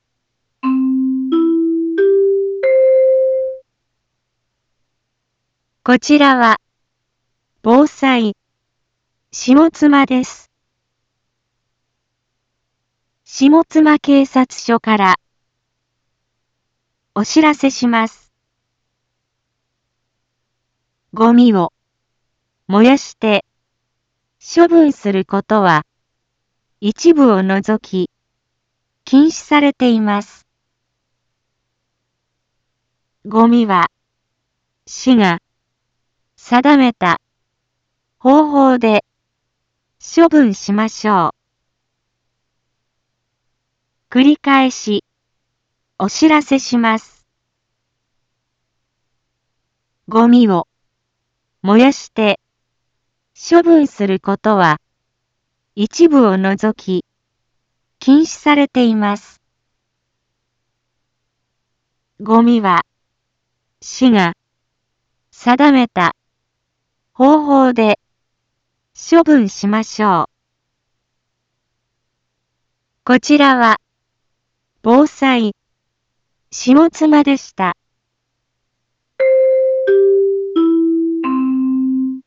Back Home 一般放送情報 音声放送 再生 一般放送情報 登録日時：2023-07-25 10:01:25 タイトル：ごみの野焼き禁止（啓発放送） インフォメーション：こちらは、防災、下妻です。